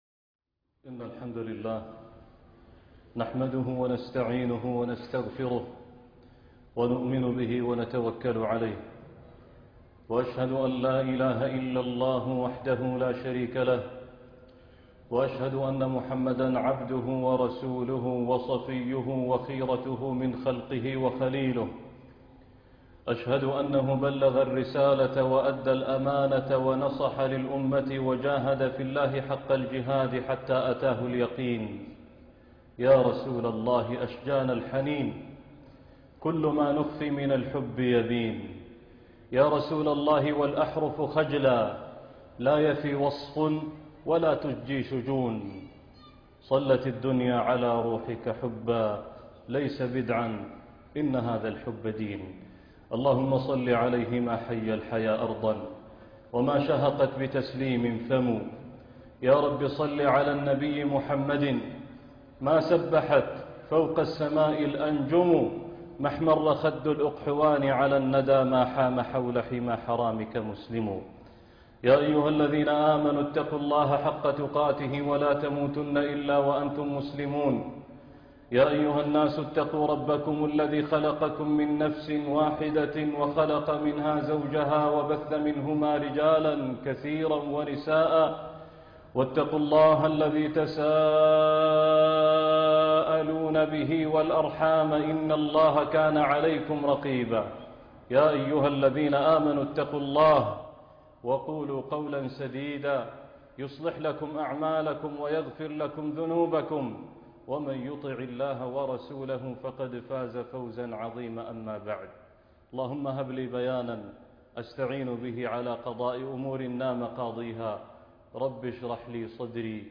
دم في نيوزلندا - خطبة الجمعة